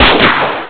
e-gun03.wav